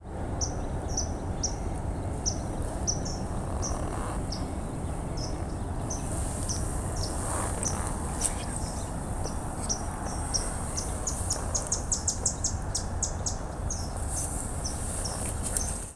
Swamp Sparrow
Melospiza georgiana